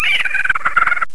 An ostrix greeting